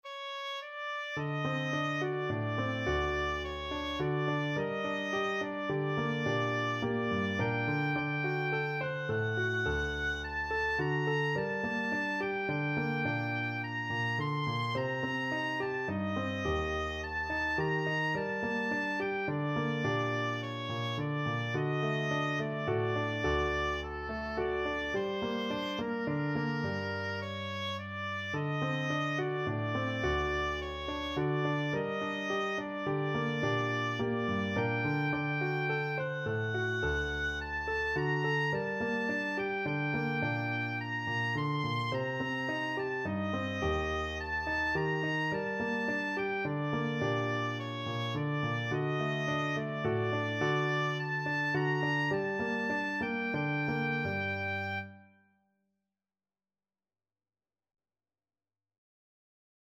Slow Waltz = c. 106
G minor (Sounding Pitch) (View more G minor Music for Oboe )
3/4 (View more 3/4 Music)
Russian